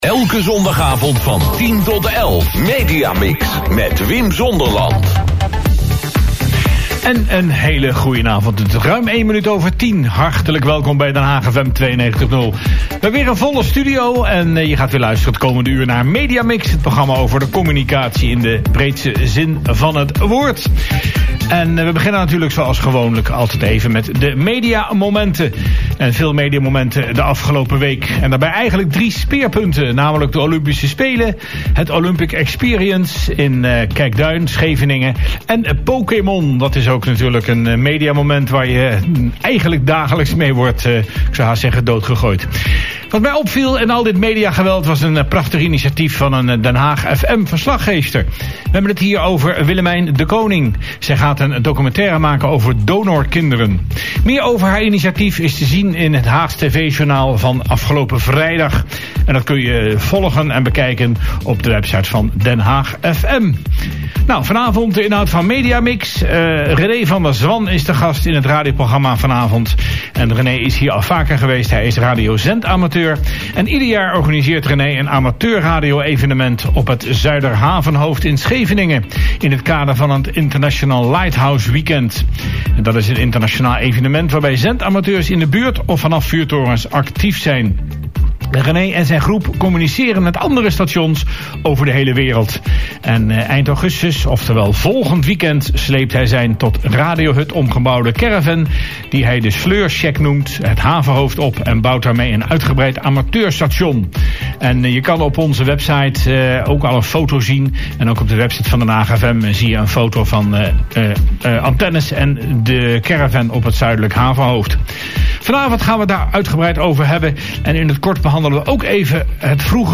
Hierbij het interview van afgelopen zondag 14 Augustus.